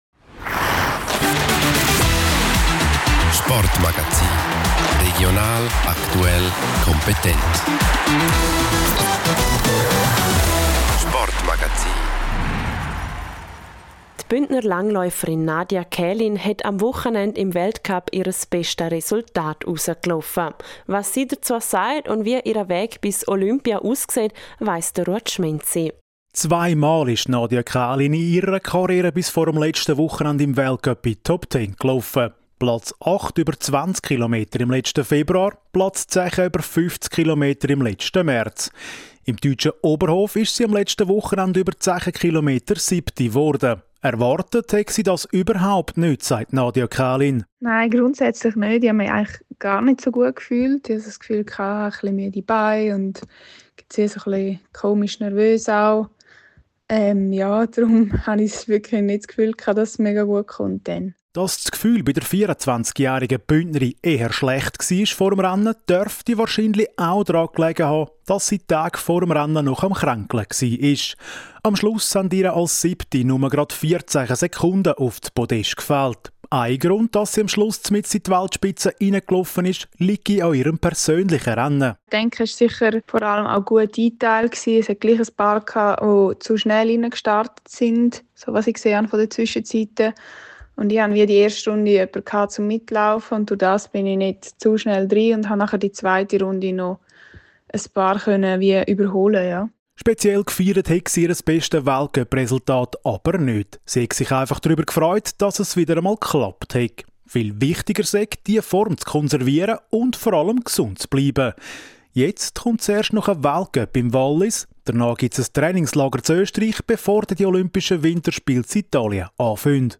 Nun wurde sie in Oberhof 7. Sie spricht über das Gefühl vor dem Rennen, über ihre Taktik und die Tage bis zu den Olympischen Winterspielen in Italien.